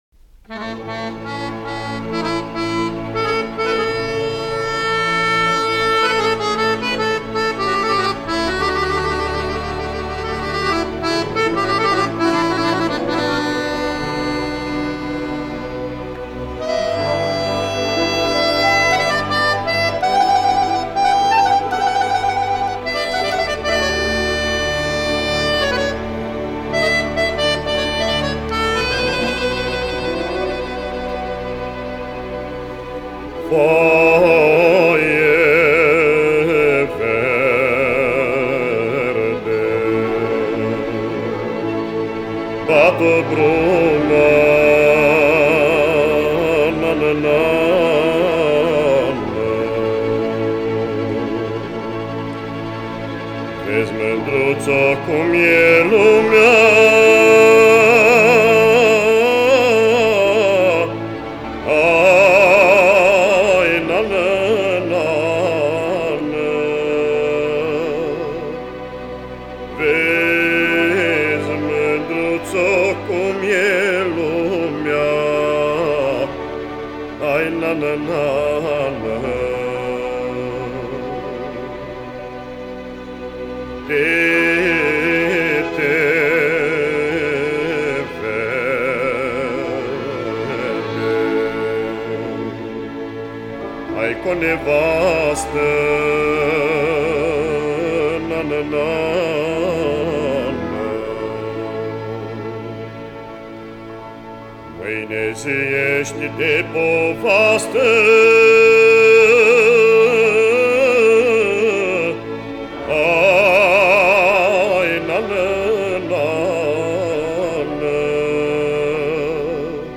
Înregistrări de arhivă